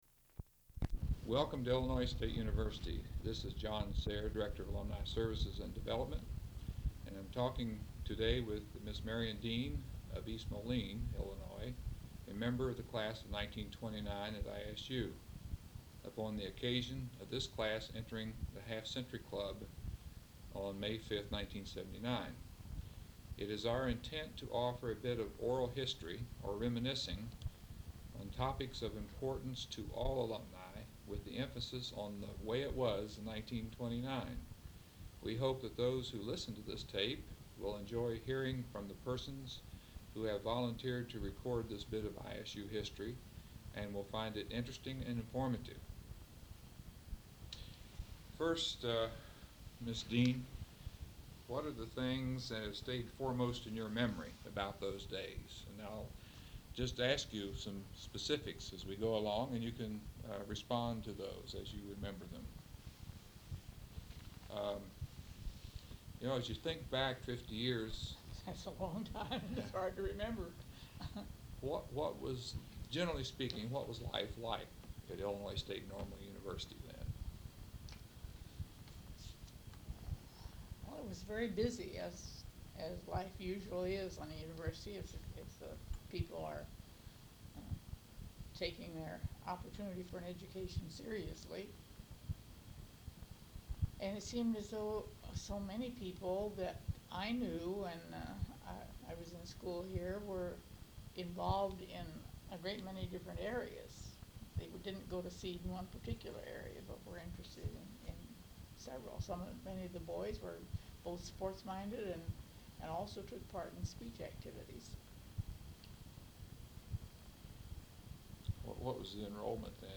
Download Interview Transcript, PDF File (781 KB)